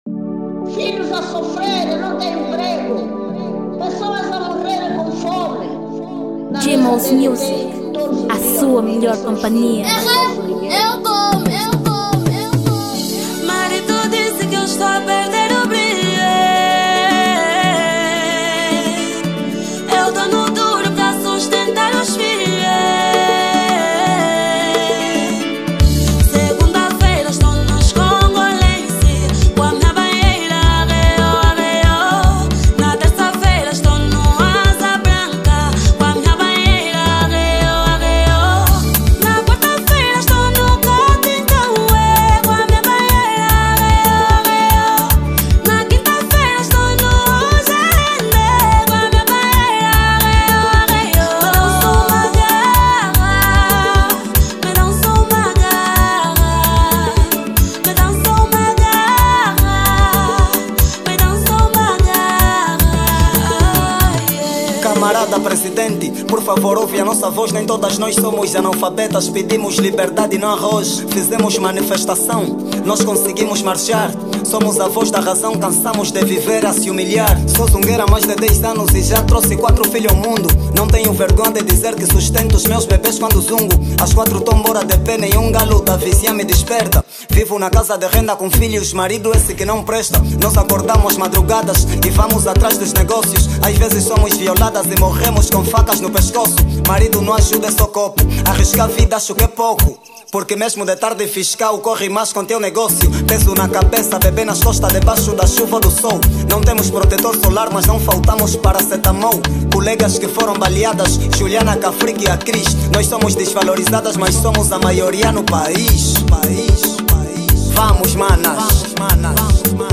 2023 Gênero: Kuduro Tamanho